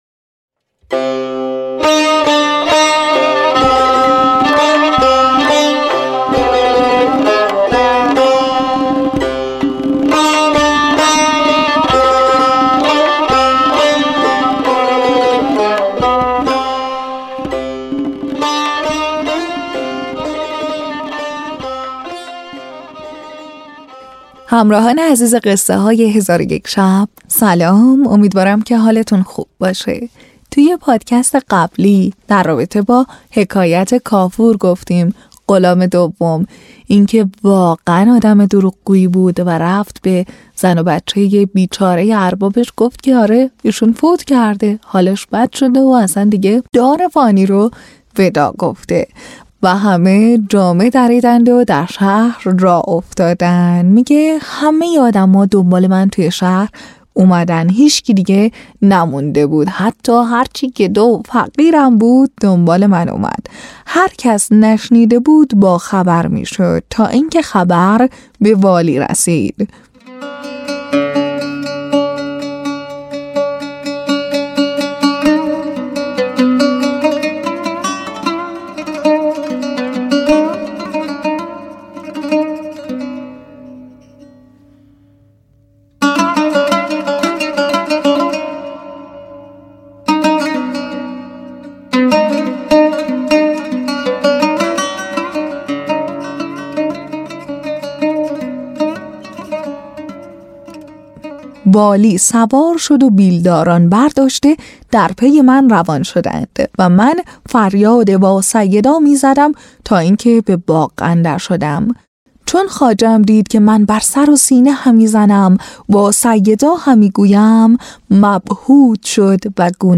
تهیه شده در استودیو نت به نت بر اساس کتاب قصه های هزار و یک شب